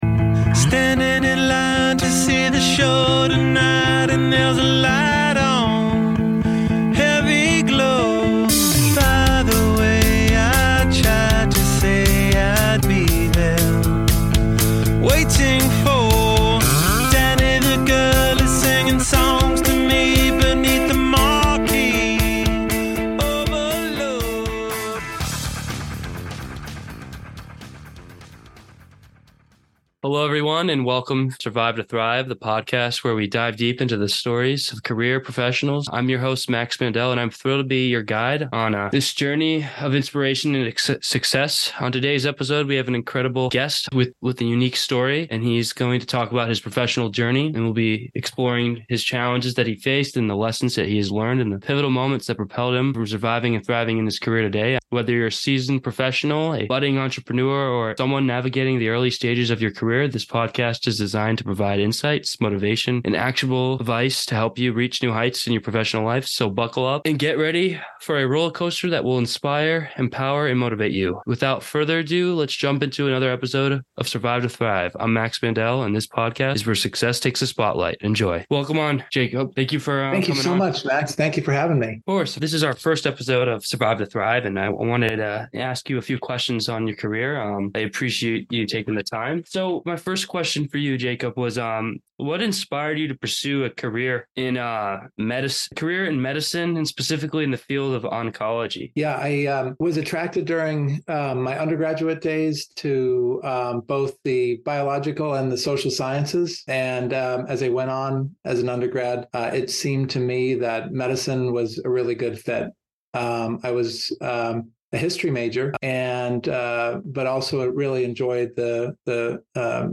0:00-0:30- Intro Music